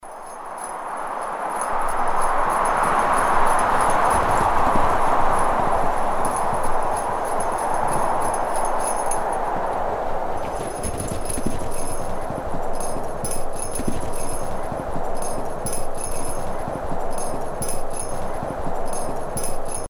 Звуки санок
Шорох саней скользящих по горе